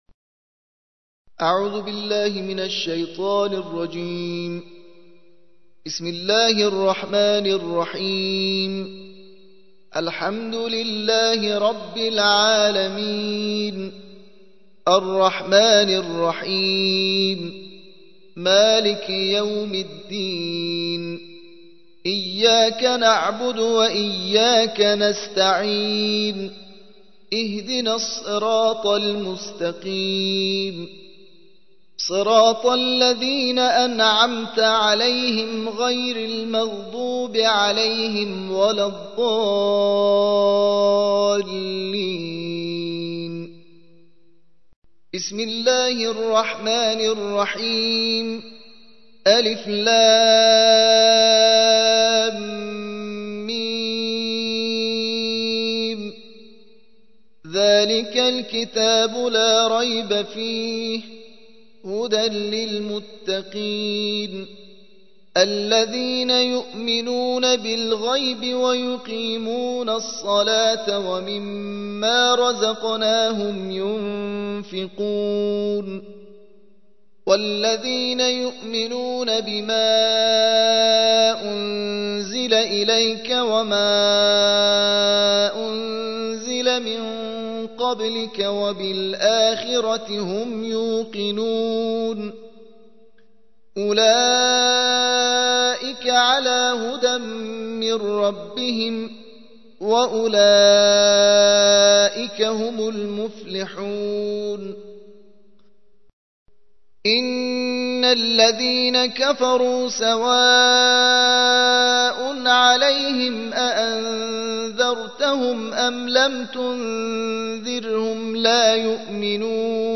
الجزء الأول / القارئ